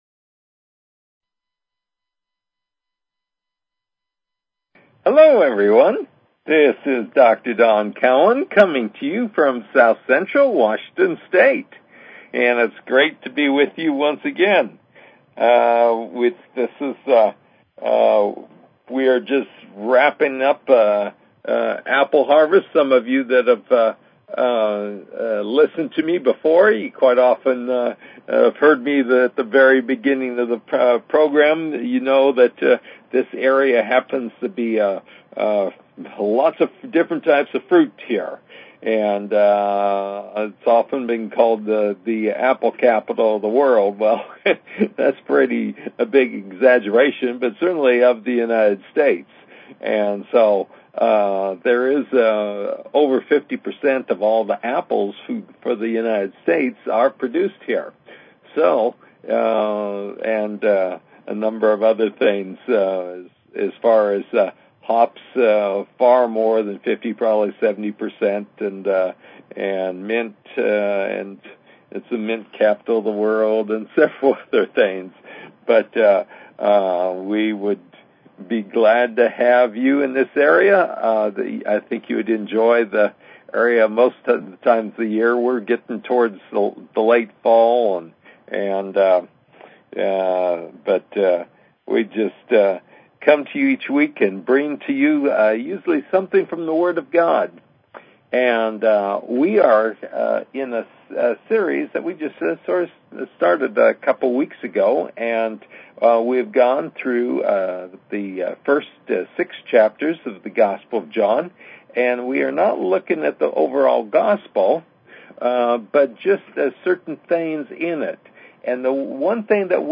Talk Show Episode, Audio Podcast, New_Redeaming_Spirituality and Courtesy of BBS Radio on , show guests , about , categorized as